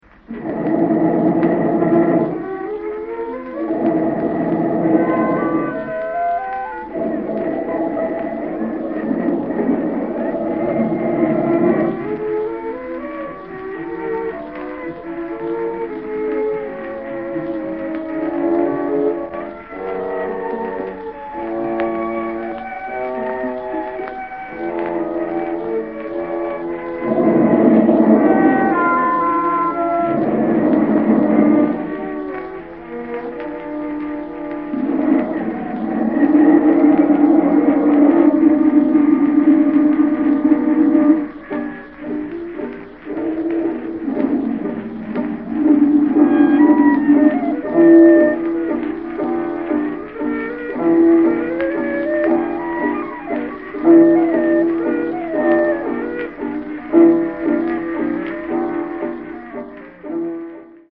Serenata" 1914 Een echte tegenstelling met de burgerlijke concertmuziek: vermenging van muziek met geruis, invloeden van jazz